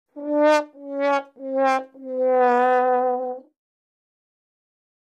Sad Trombone